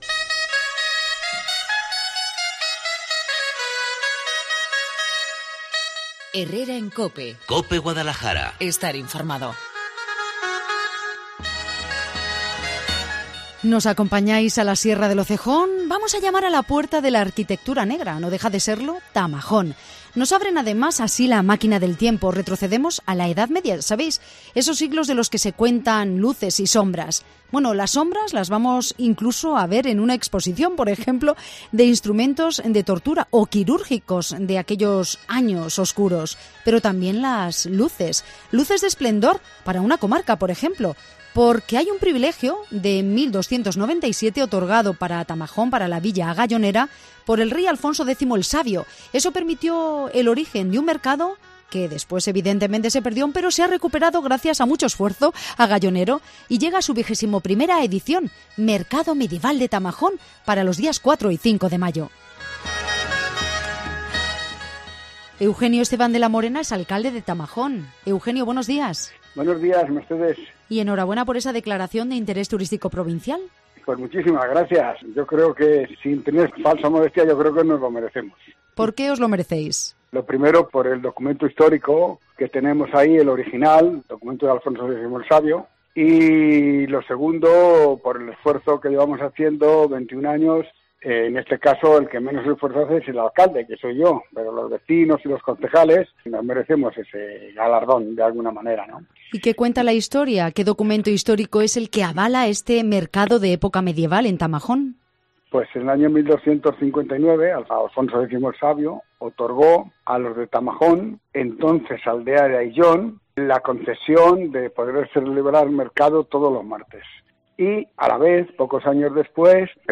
El alcalde de Tamajón, Eugenio Esteban de la Morena, ha adelantado a Cope Guadalajara las citas más destacadas e interesantes de la programación del Mercado medieval agallonero.